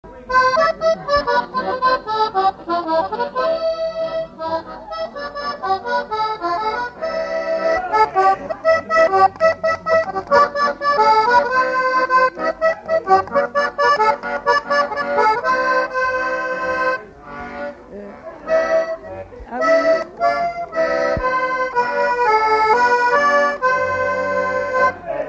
Figure de quadrille
instrumental
danse : quadrille : galop
Veillée de collectage de chants et de danses
Pièce musicale inédite